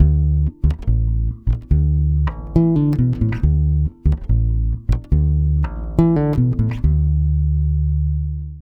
140BASS D7 4.wav